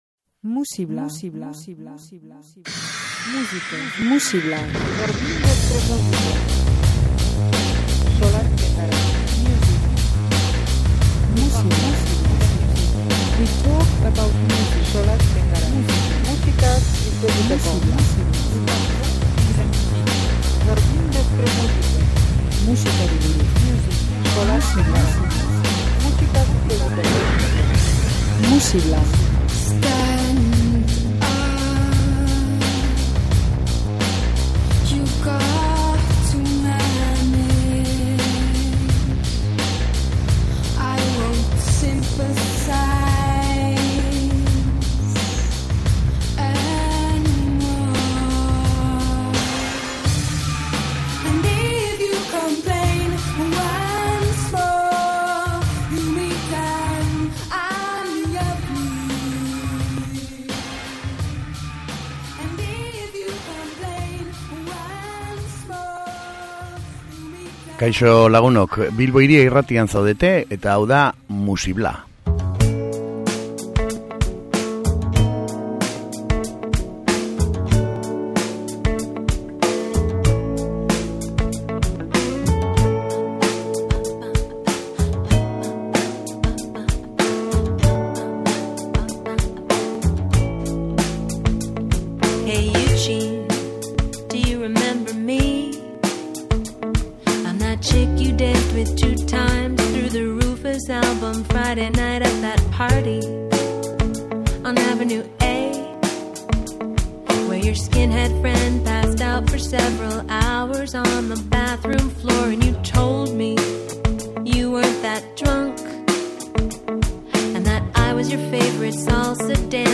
Soinu ugari, desberdin eta erakargarriak.